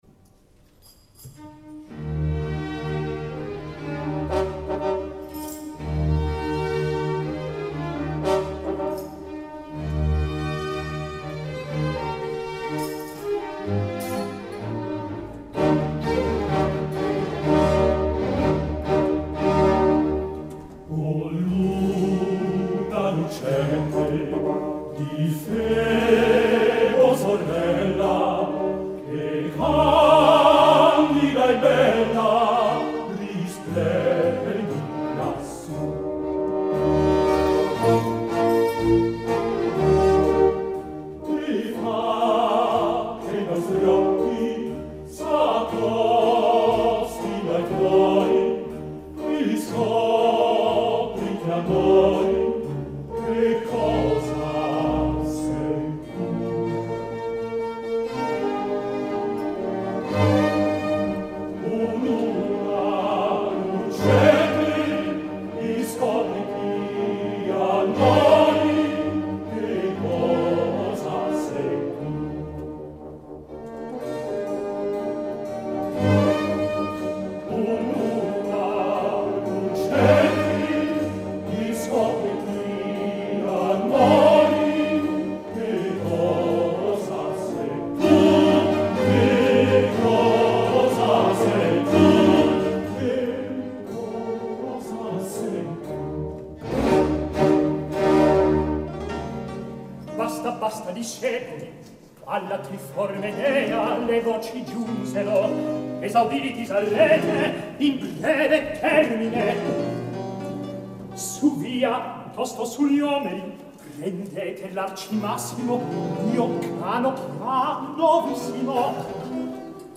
August 2001 aus dem Landestheater Tirol mit der Akademie für Alte Musik, Berlin (Leitung: René Jacobs). Die selig entrückten Mondklänge: Coro (mit Recitativo) am Beginn des 1. Aktes und Sinfonia zu Beginn des 2. Aktes.